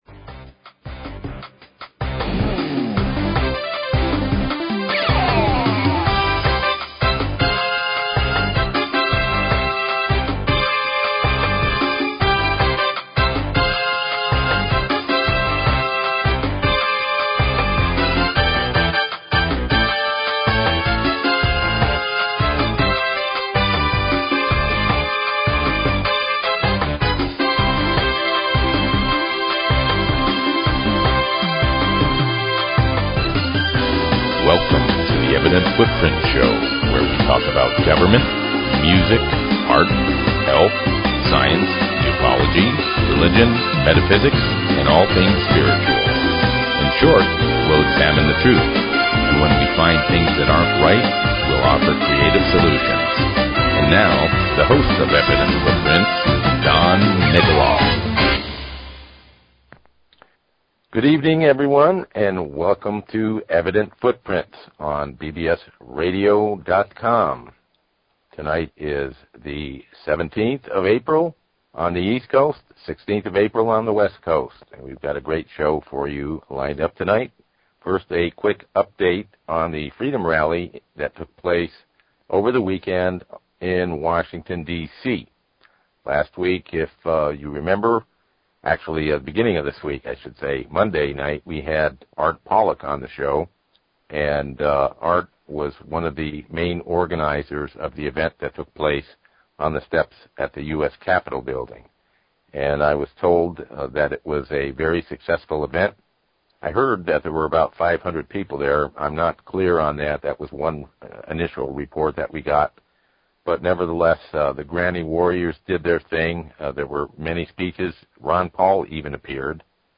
Talk Show Episode, Audio Podcast, Evident_Footprints and Courtesy of BBS Radio on , show guests , about , categorized as
To put it bluntly, this interview was a classic!